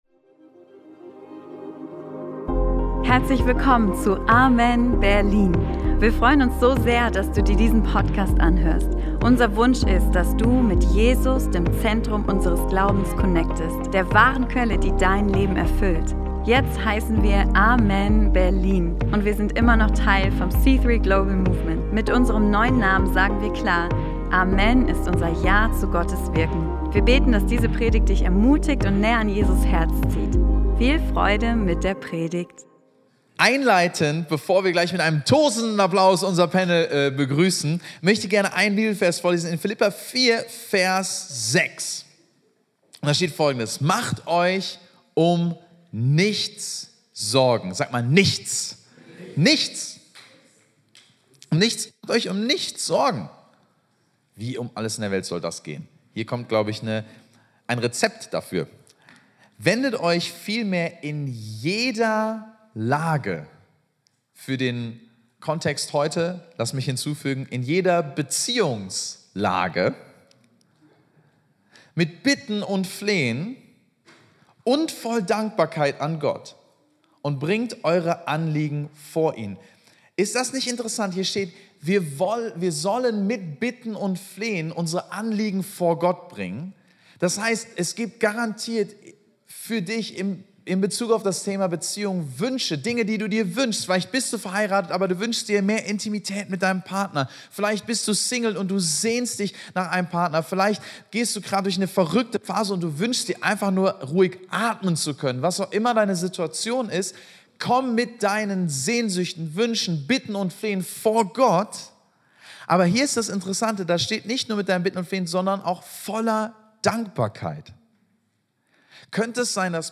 Single. Verheiratet. Geschieden. – Unser ehrlicher Panel Talk – COMMITMENT ISSUES ~ AMEN Berlin Podcast
Beschreibung vor 3 Monaten In dieser besonderen Folge nehmen wir dich mit in ein ehrliches Gespräch über das Leben als Single, in der Ehe und nach einer Scheidung.